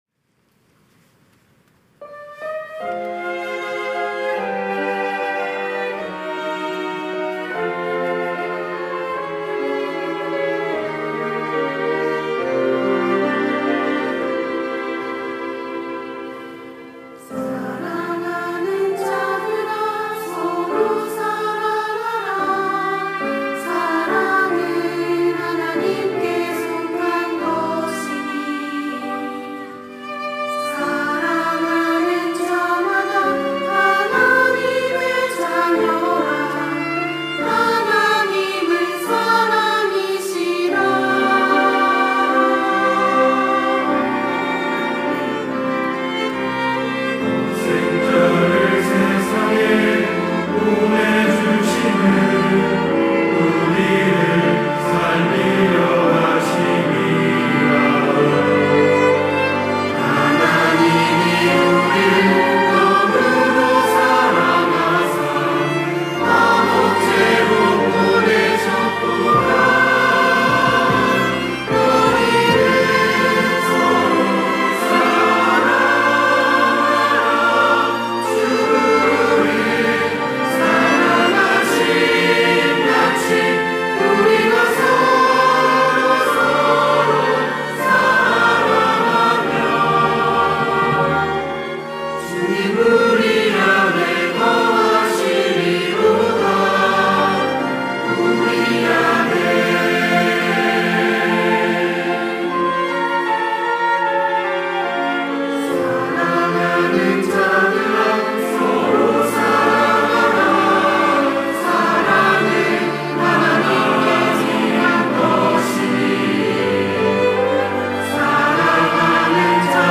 특송과 특주 - 너희는 서로 사랑하라
청년부 양육 2팀